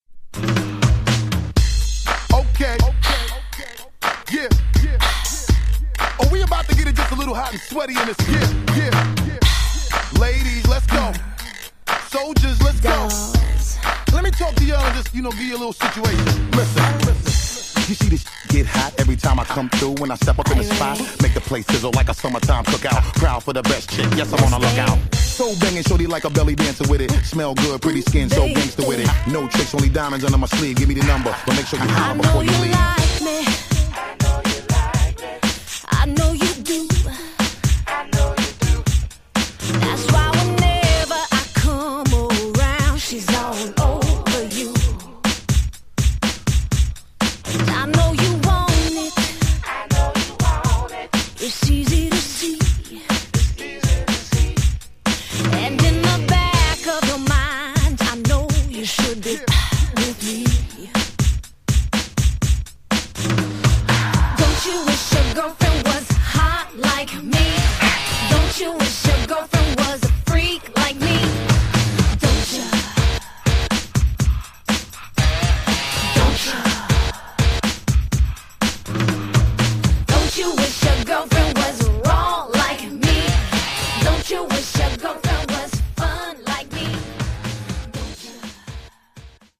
122 bpm